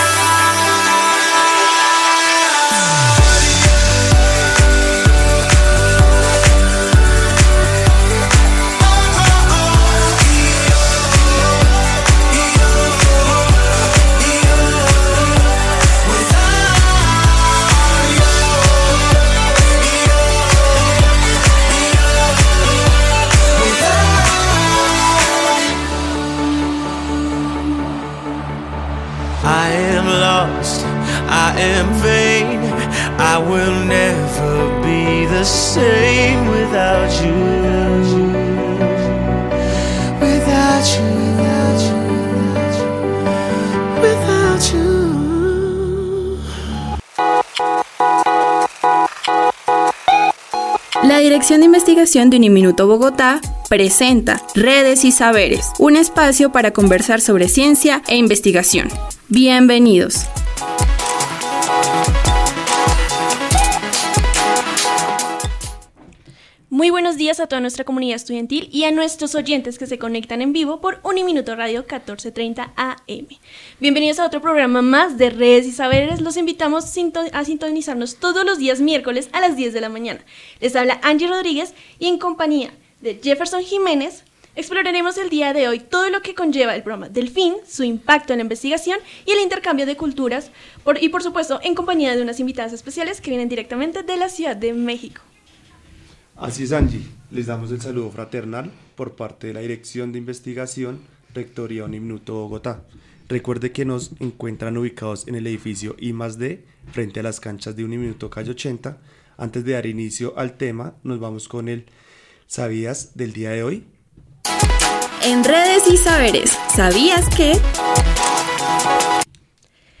Redes y Saberes Espacio de radio donde se difunde y recopila el conocimiento, analiza la información para una mejor comprensión de los temas e incentiva a la investigación.